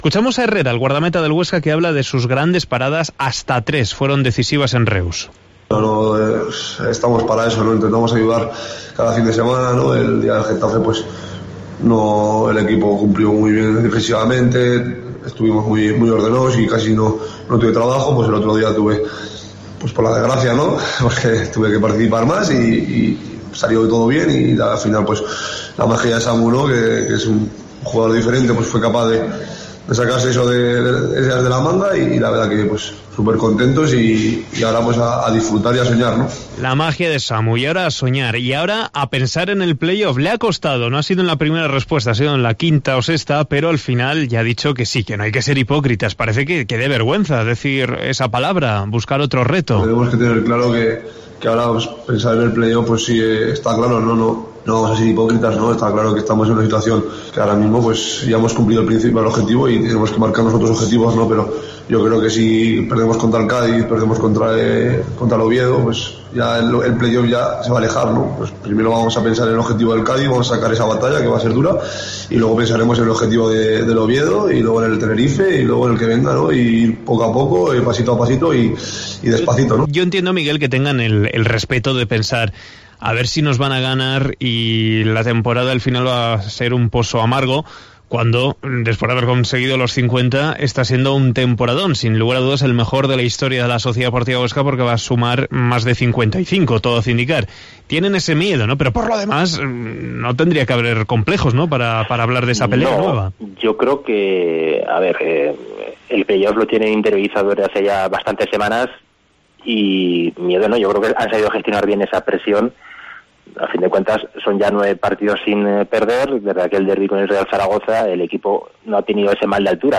Escuchamos las declaraciones de Sergio Herrera en zona mixta